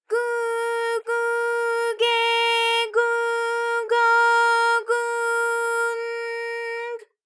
ALYS-DB-001-JPN - First Japanese UTAU vocal library of ALYS.
gu_gu_ge_gu_go_gu_n_g.wav